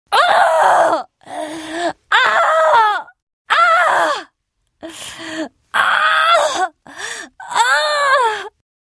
Звук дыхания и выдохов женщины во время схваток в роддоме